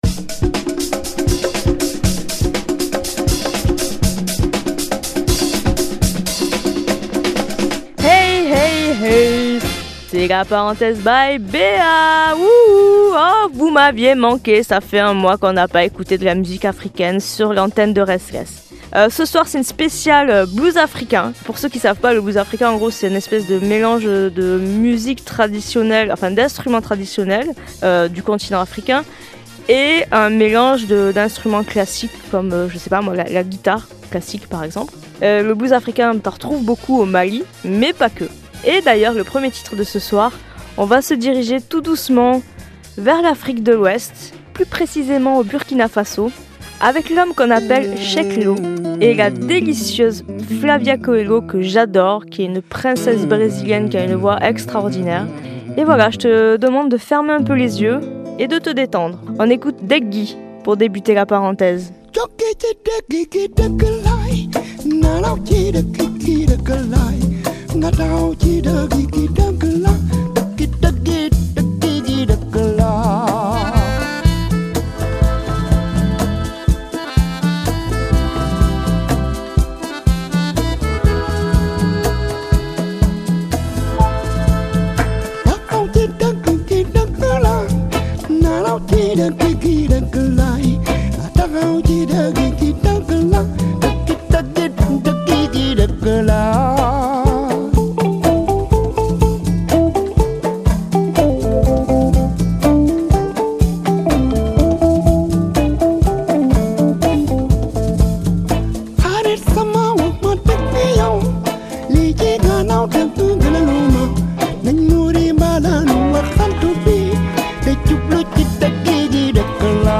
Ce soir, sélection des plus grands bluesmen africains !